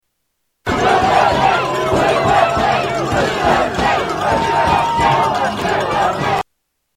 The crowd chants USA